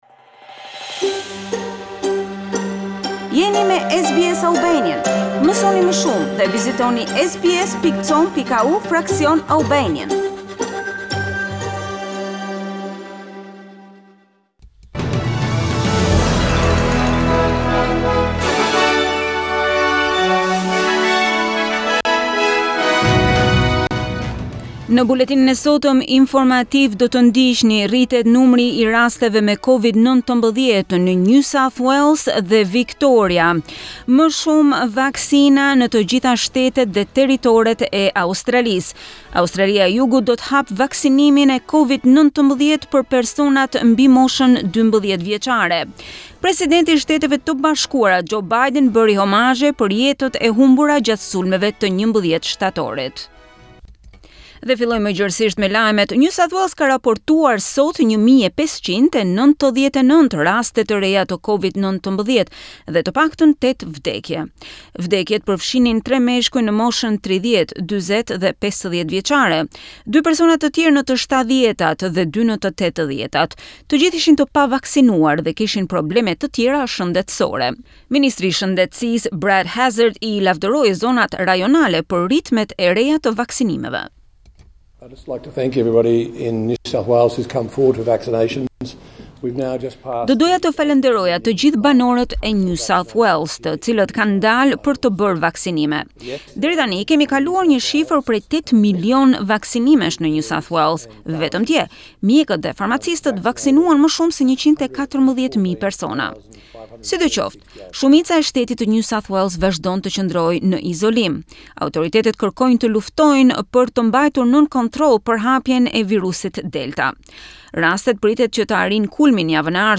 SBS News Bulletin in Albanian- 11 September 2021